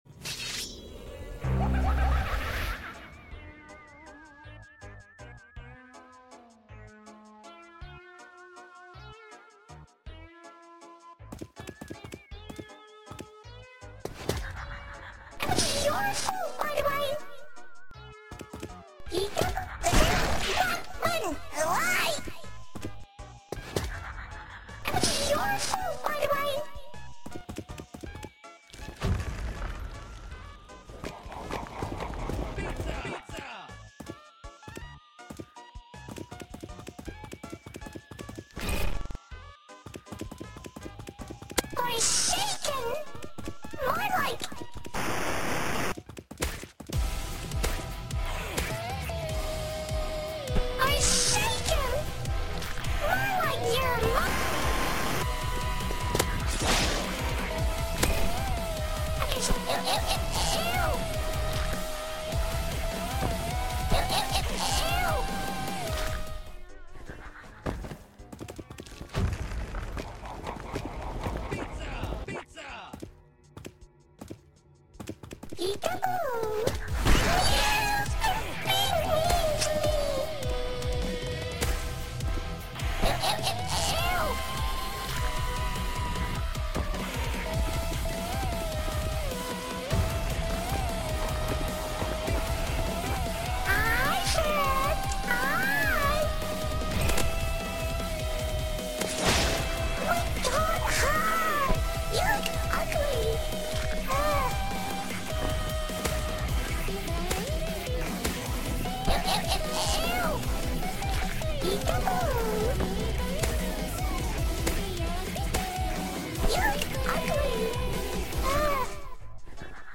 it use’s the offcial voicelines